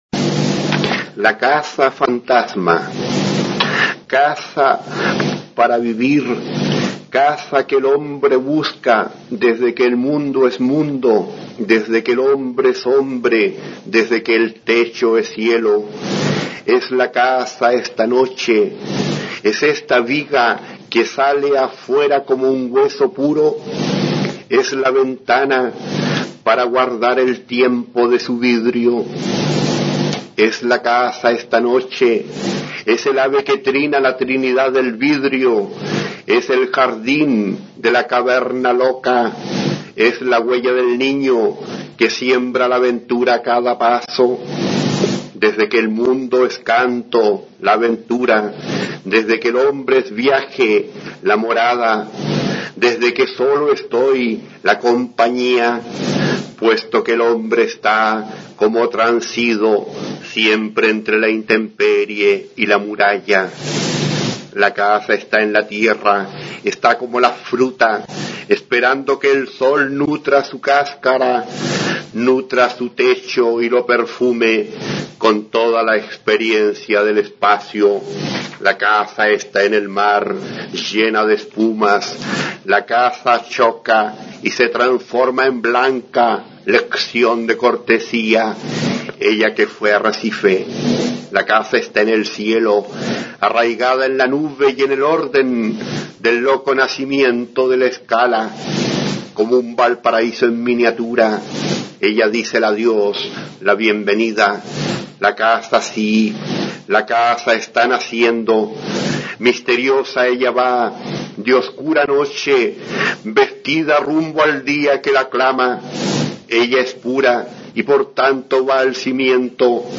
Aquí se puede oír al escritor chileno Braulio Arenas (1913-1988), recitando su poema La casa fantasma.